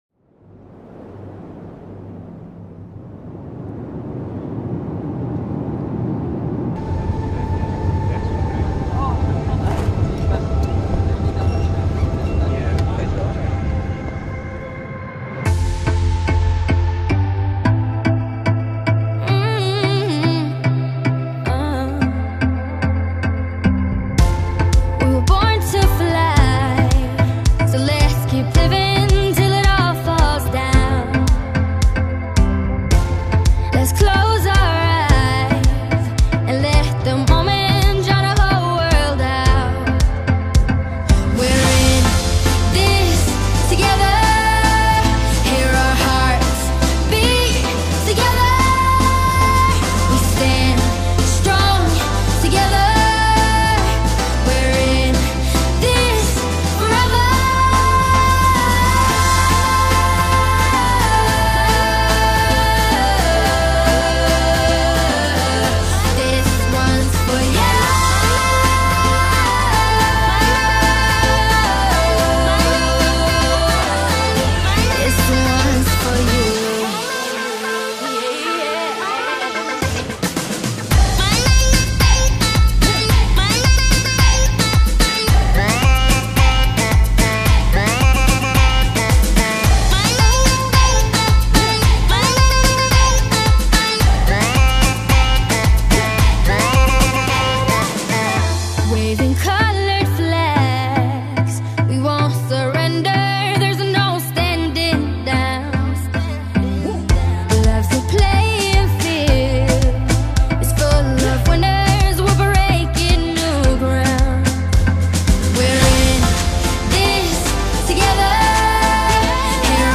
سبک موسیقی بی کلام